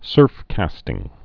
(sûrfkăstĭng)